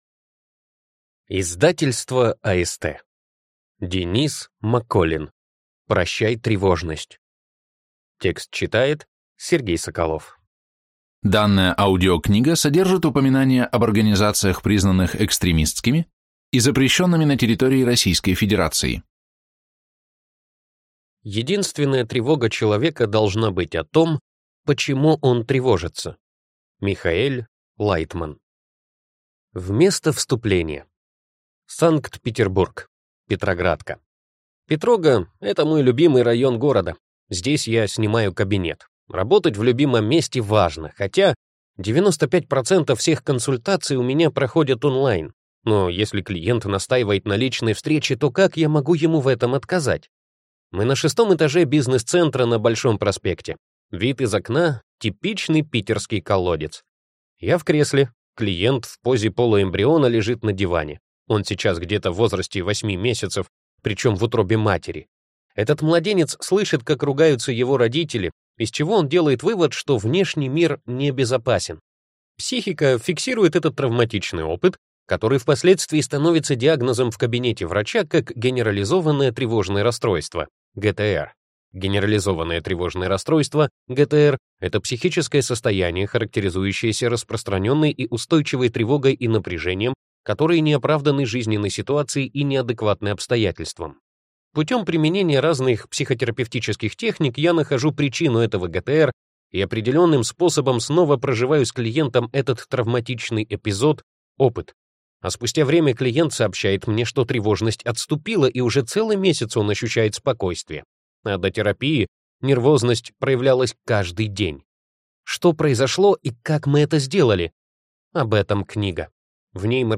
Аудиокнига Прощай, тревожность!